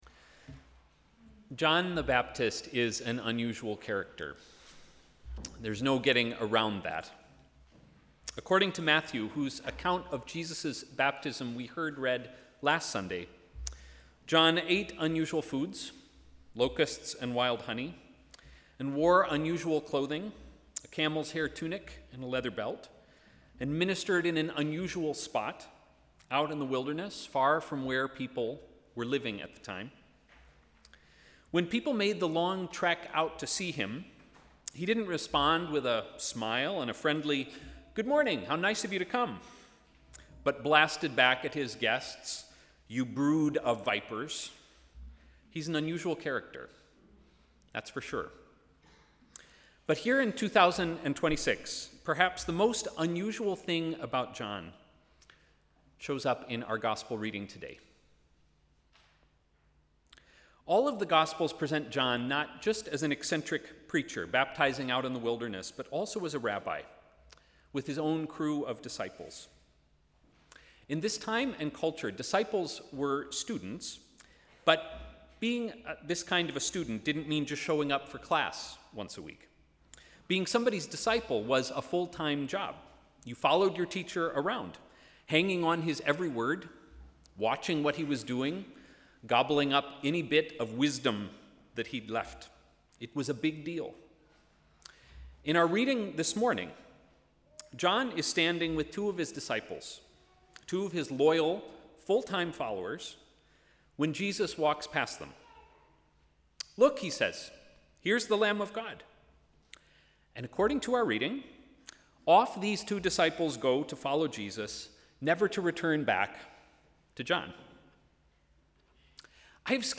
Sermon: ‘Bearing witness’